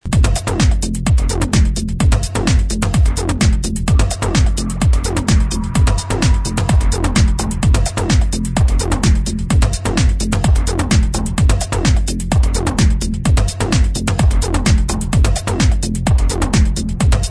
prog house track 2004-2005